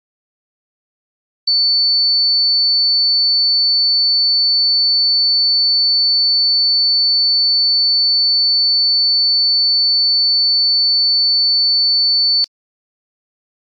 60歳以上も聞こえる音。鈴虫の声の周波数は約4500Hzhほど。